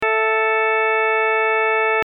First, here are some registrations consisting of combinations of some of the stops from the previous section, with drawbar settings and audio clips:
bassoon_frenchtrumpet.mp3